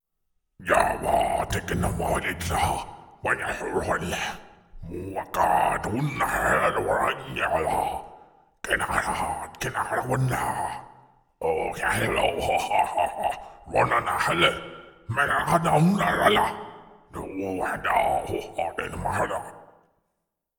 Extraterrestre hablando
Sonidos: Especiales
Sonidos: Comunicaciones
Sonidos: Fx web